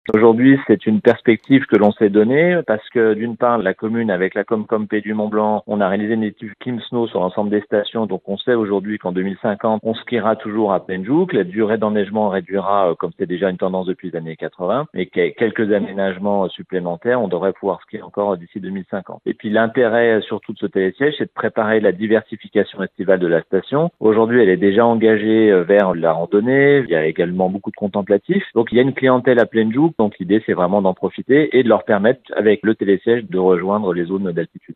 C’est le pari en tout cas du maire de Passy Raphael Castera :